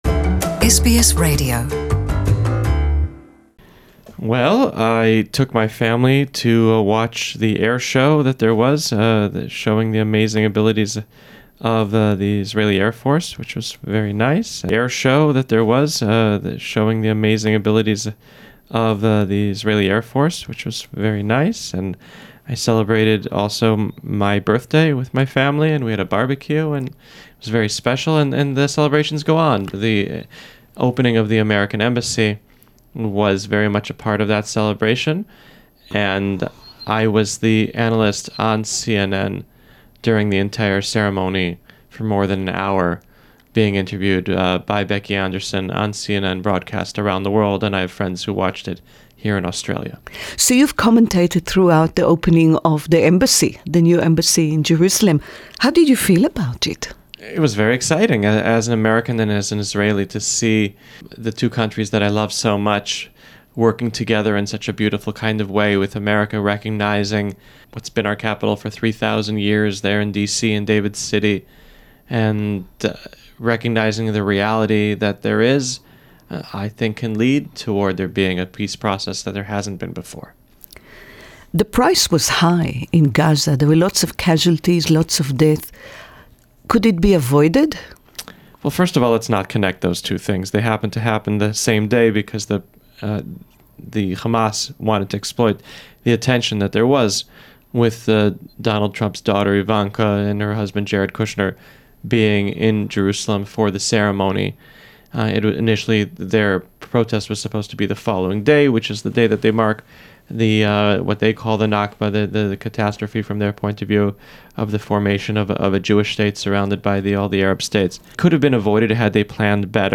"The Jerusalem Post" English Interview